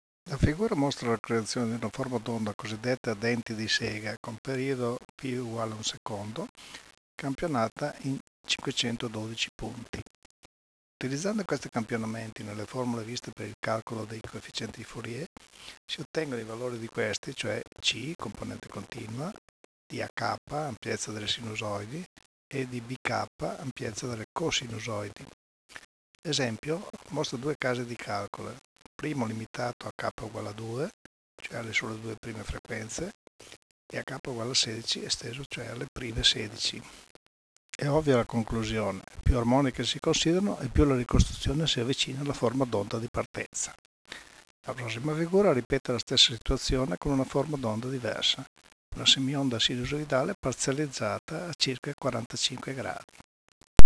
[commento audio])